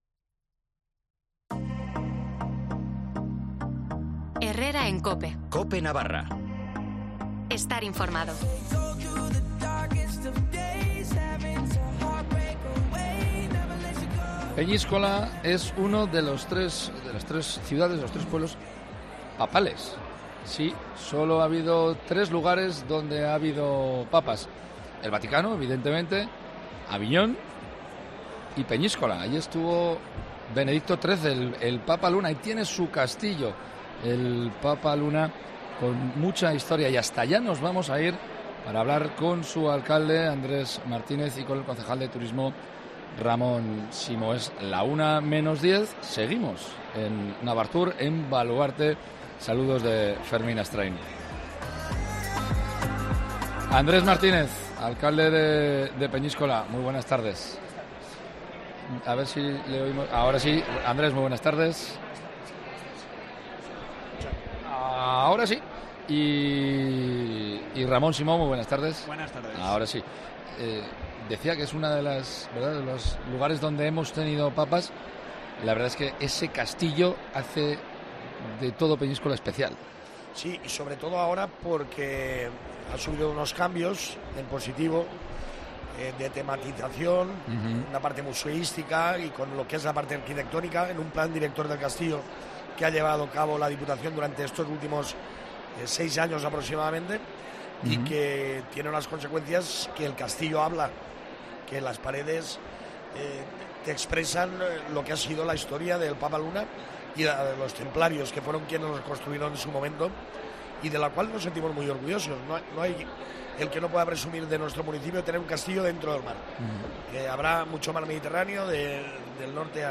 Entrevista con Andrés Martínez y Ramón Simó, alcalde y concejal de Peñíscola